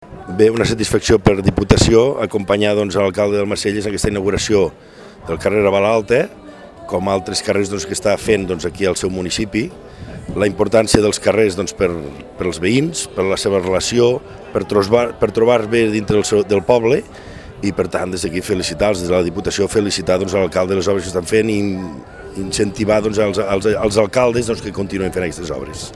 El diputat Antoni Villas ha estat present a la inauguració i ha participat en els parlaments oficials que ha obert l’alcalde d’Almacelles, Josep Ibarz. En la seva intervenció, Villas ha destacat la gran tasca que s’està duent a terme a Almacelles i ha reafirmat el compromís de la Diputació en el procés de millora dels pobles i ciutats del territori incentivant als alcaldes ha seguir en aquest camí.
Declaracions-Sr.-Antoni-Villas.mp3